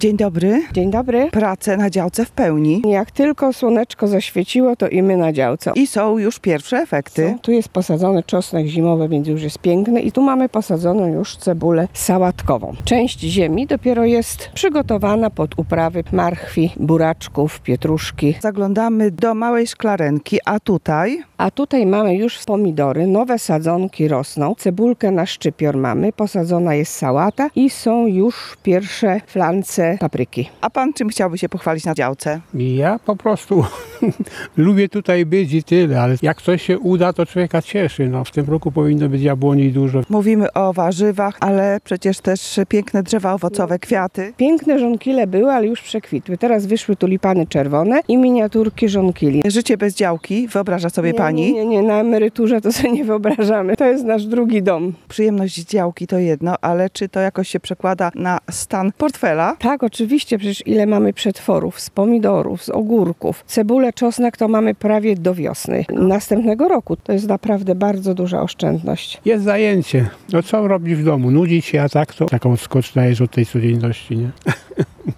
Ogródki działkowe ożyły w ostatnich dniach, dzięki wiosennej aurze, a wśród grządek uwijają się ich właściciele. Jak powiedzieli nam działkowcy z Rzeszowa, praca w ogródku jest sposobem na relaks i oderwanie się od codziennych spraw. Zwracają też uwagę na aspekt ekonomiczny.